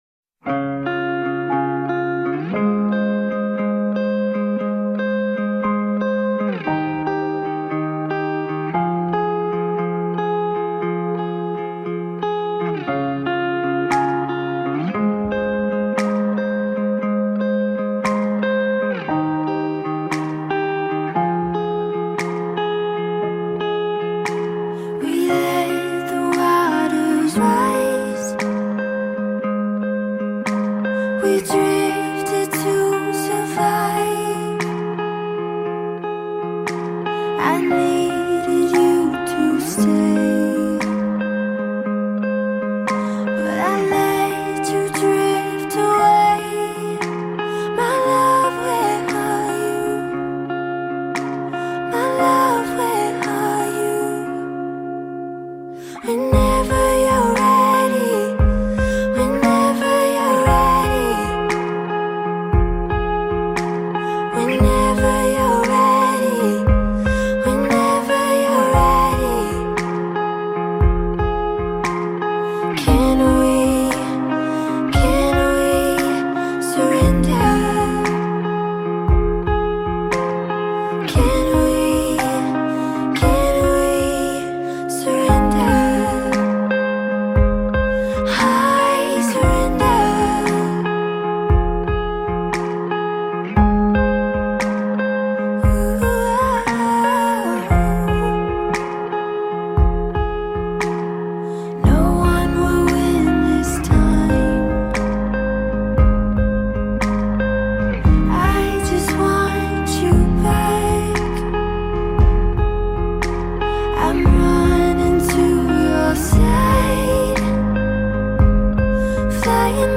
The American contemporary singer, songwriter, and musician